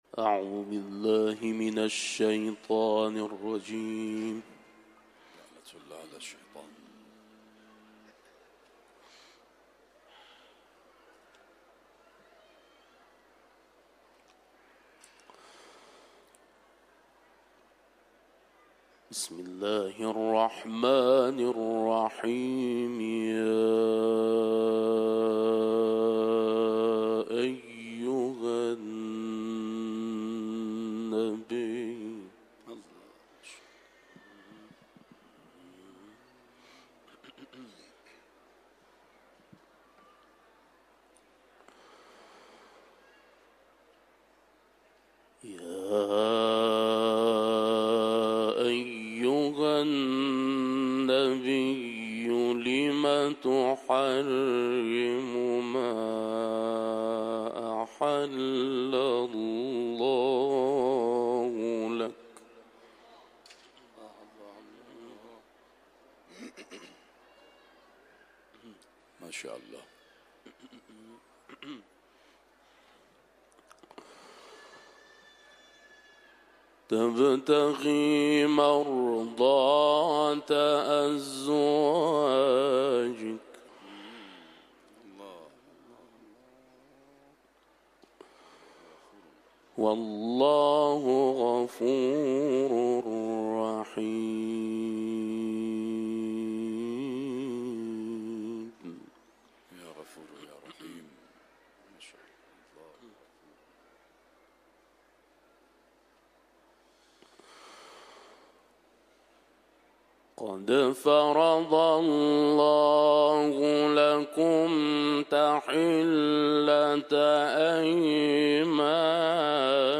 Kur’an tilaveti